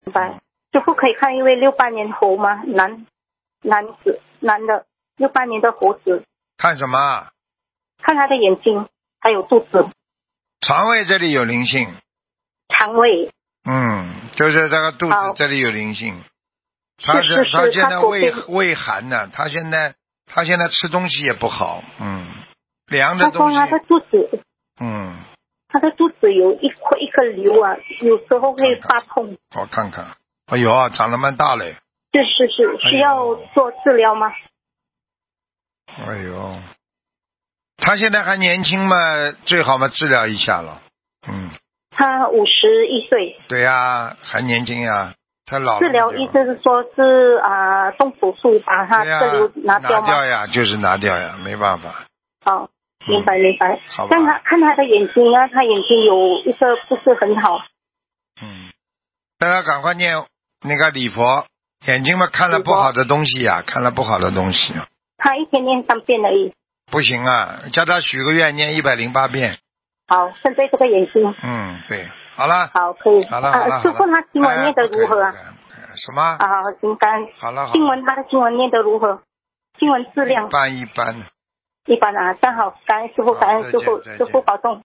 目录：2019年12月_剪辑电台节目录音_集锦